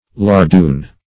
Search Result for " lardoon" : The Collaborative International Dictionary of English v.0.48: Lardon \Lar"don\, Lardoon \Lar*doon"\, n. [F. lardon, fr. lard lard.] A bit of fat pork or bacon used in larding.